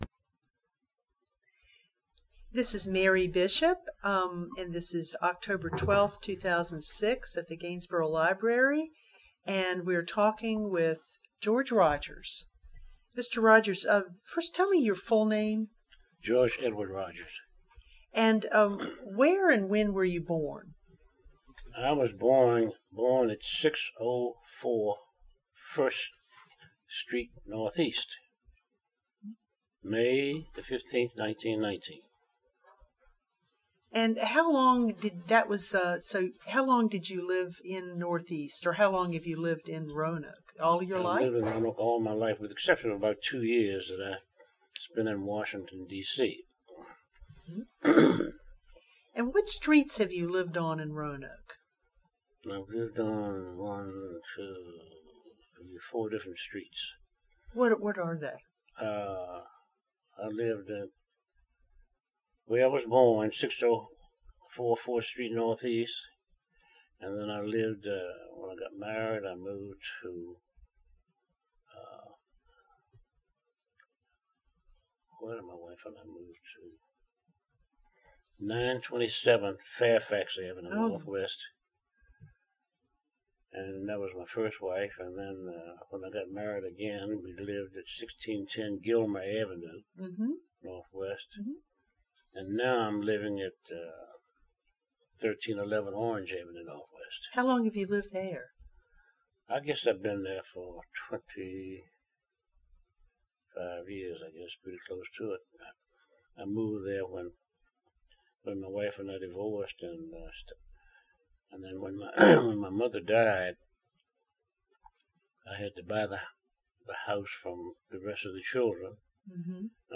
Neighborhood History Interview
Location: Gainsboro Branch Library